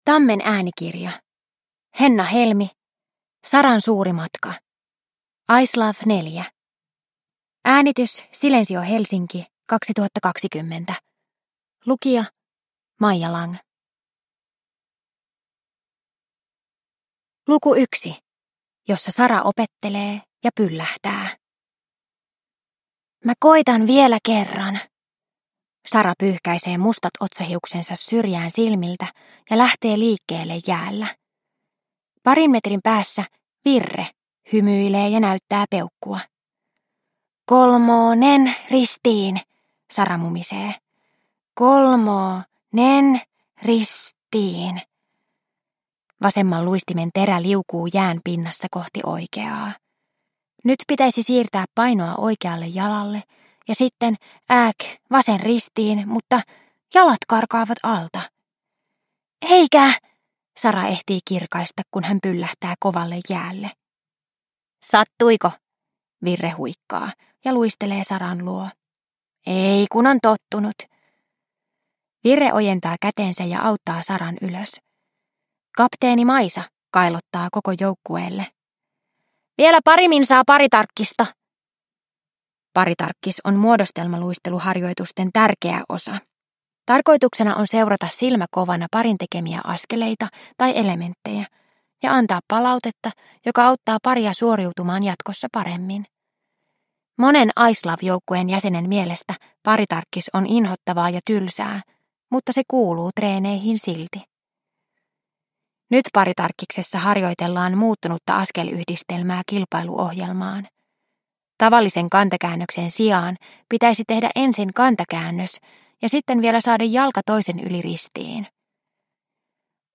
Saran suuri matka – Ljudbok